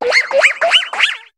Cri d'Otarlette dans Pokémon HOME.